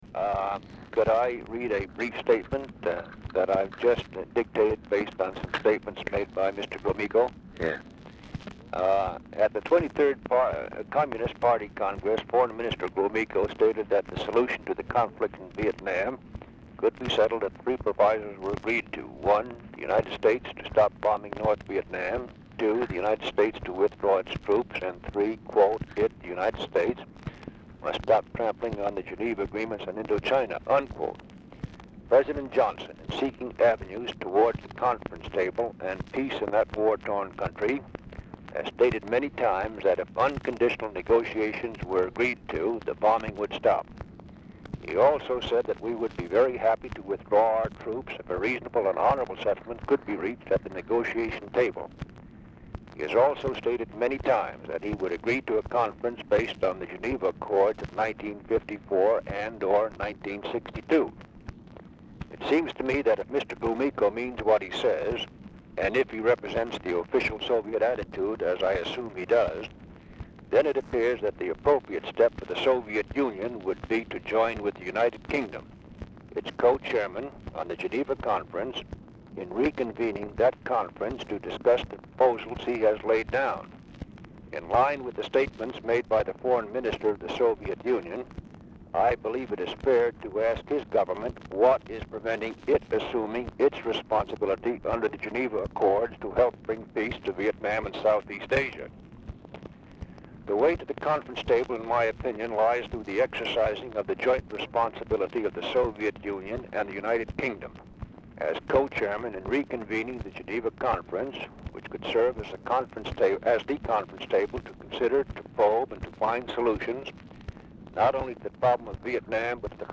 Telephone conversation # 10005, sound recording, LBJ and MIKE MANSFIELD, 4/2/1966, 10:14AM | Discover LBJ
RECORDING STARTS AFTER CONVERSATION IS OVER; LBJ IS MEETING WITH BILL MOYERS AT TIME OF CALL, ASKS HIM TO LISTEN IN ON CONVERSATION
Format Dictation belt
Location Of Speaker 1 Mansion, White House, Washington, DC